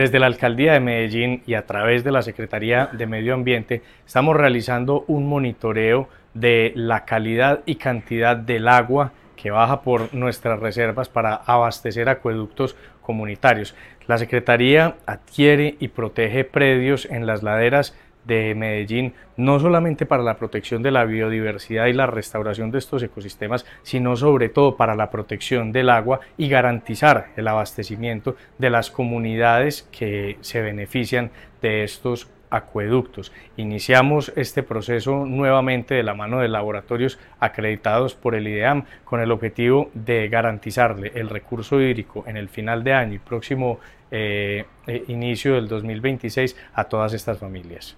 Declaraciones subsecretario de Recursos Naturales, Esteban Jaramillo
Declaraciones-subsecretario-de-Recursos-Naturales-Esteban-Jaramillo.mp3